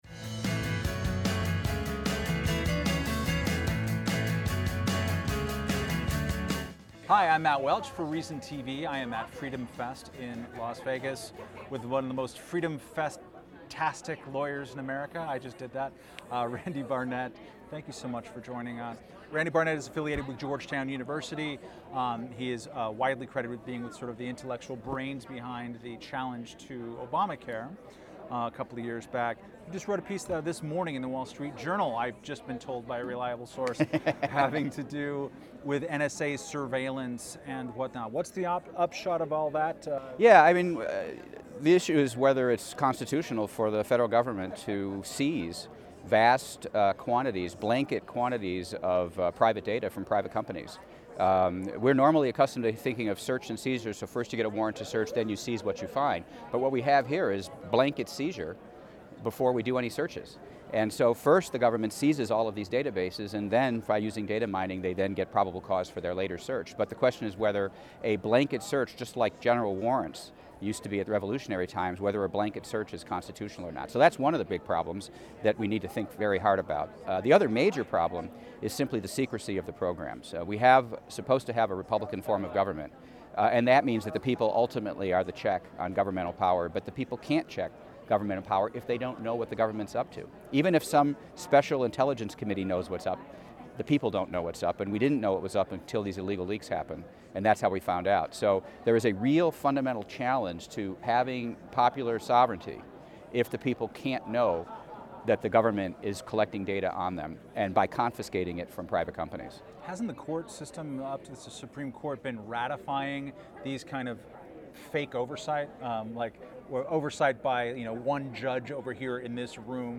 Reason's Matt Welch caught up with Barnett at Freedom Fest to discuss the National Security Agency (NSA).